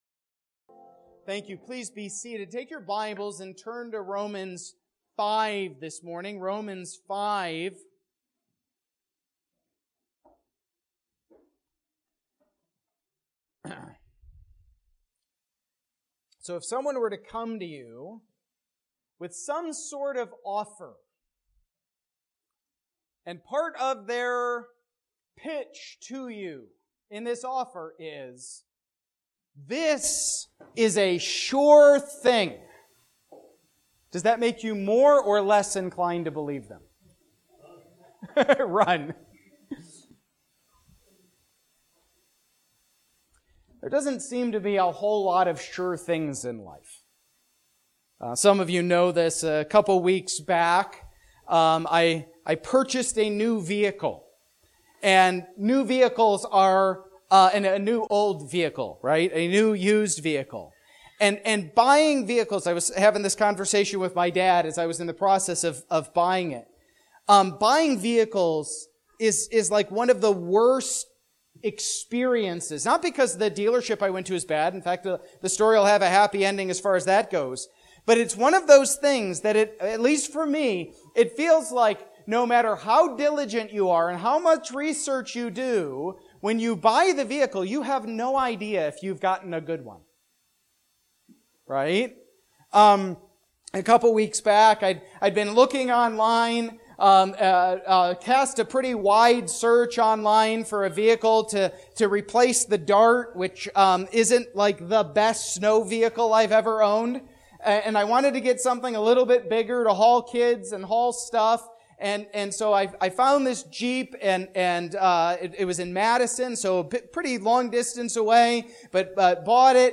A message from the series "Romans."